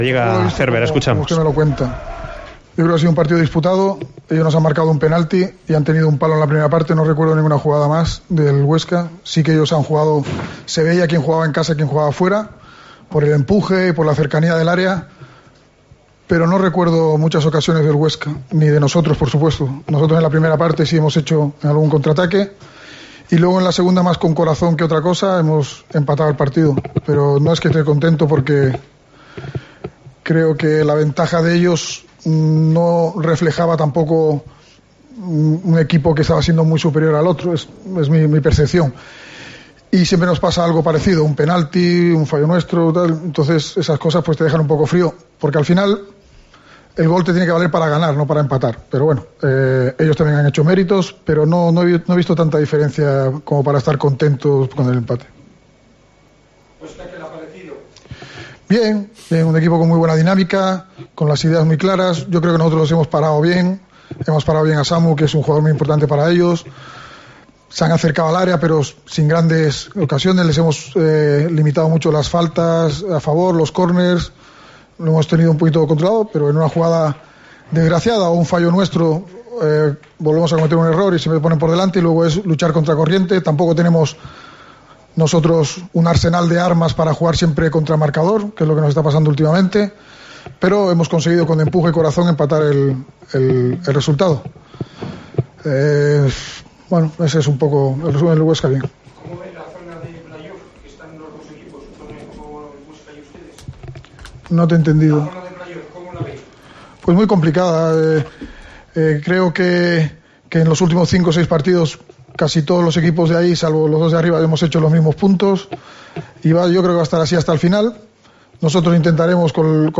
Declaraciones del entrenador del Cádiz después de empatar a uno frente al Huesca en el último suspiro del encuentro.